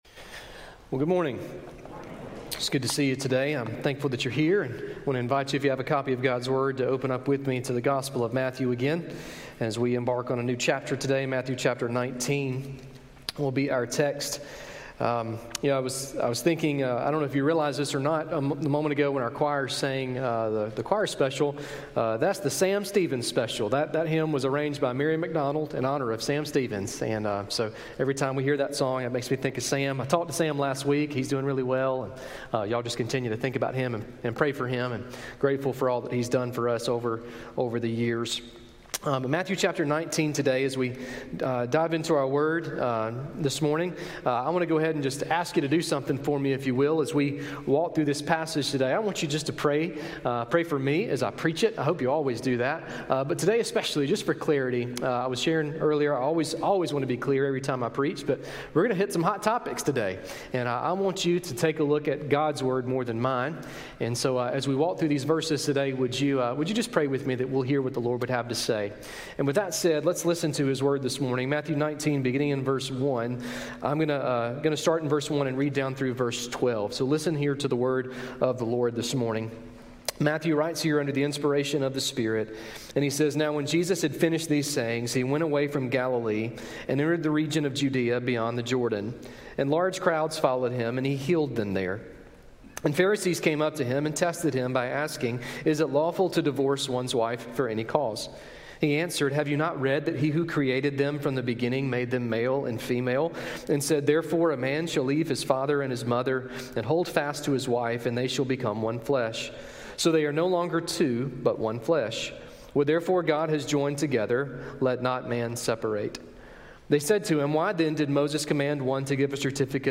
A message from the series "We're Not Home Yet."